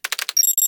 MiniKeyboard.wav